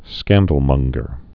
(skăndl-mŭnggər, -mŏng-)